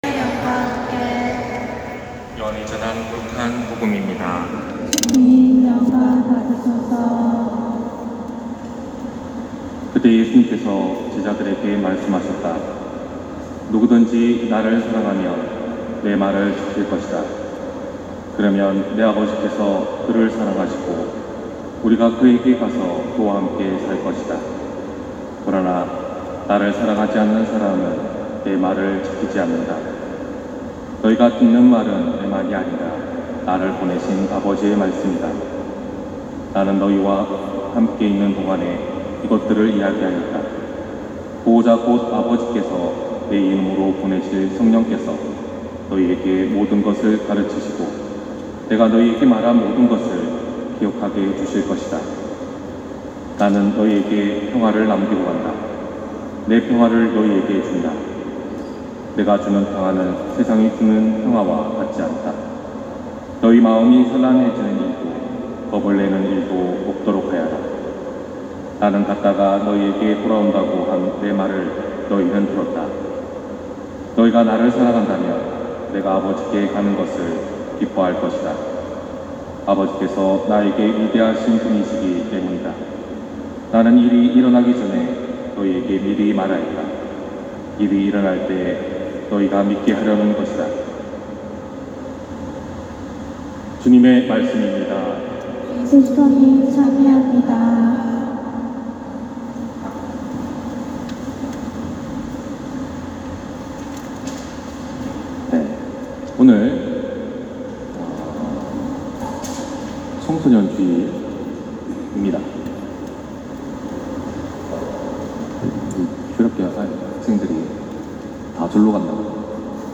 250524신부님 강론말씀